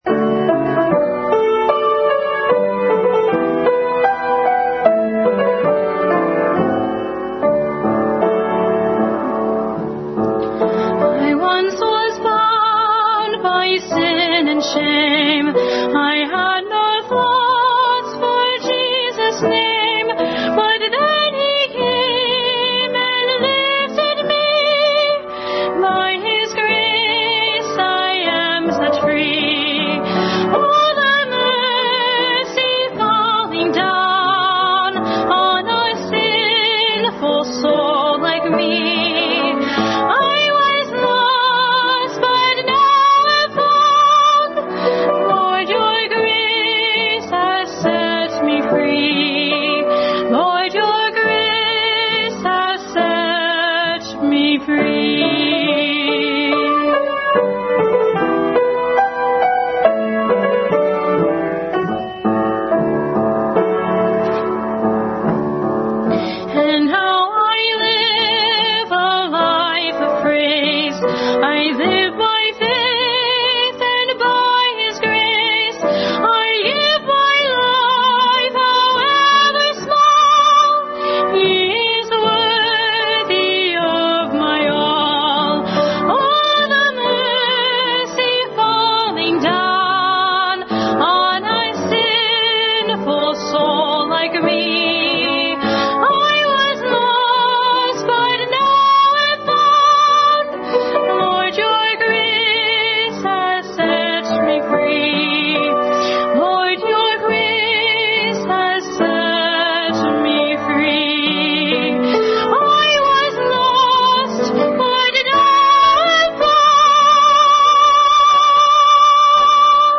Service Type: Family Bible Hour
Family Bible Hour Message.